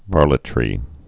(värlĭ-trē)